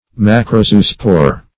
Search Result for " macrozoospore" : The Collaborative International Dictionary of English v.0.48: Macrozoospore \Mac`ro*zo"o*spore\, n. [Macro- + zoospore.]
macrozoospore.mp3